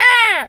seagul_squawk_hurt_01.wav